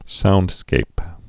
(soundskāp)